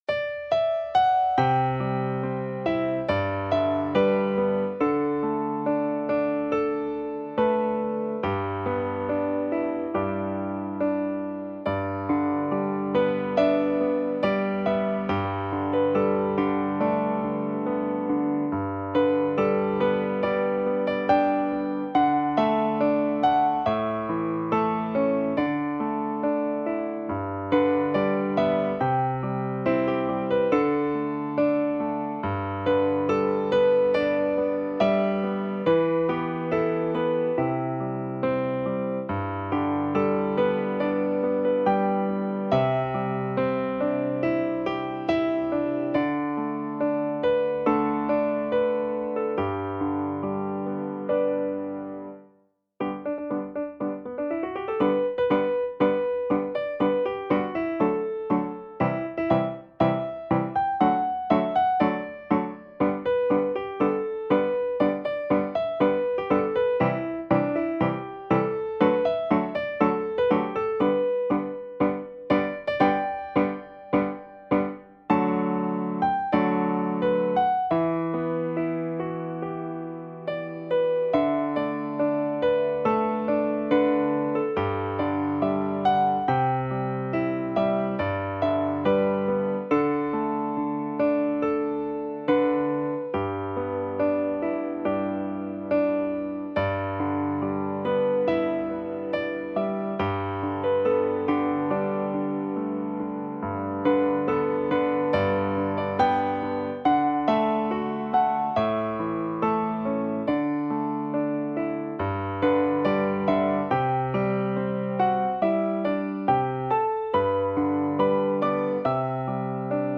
钢琴的音质素来明净如水，它很容易吹走尘世浮华。